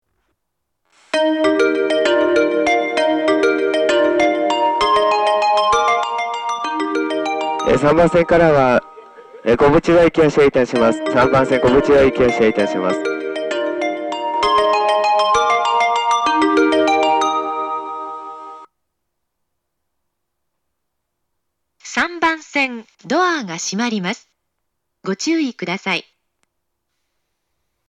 発車メロディー
1.9コーラスです。